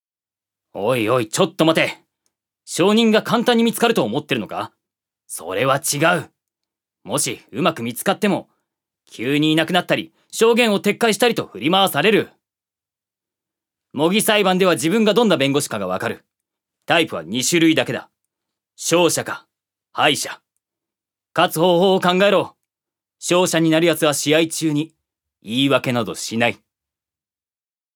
預かり：男性
セリフ３